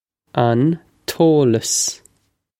on toh-luss
This is an approximate phonetic pronunciation of the phrase.